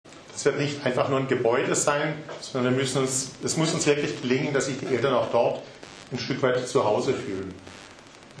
Pressekonferenz "Neubau Elternhaus"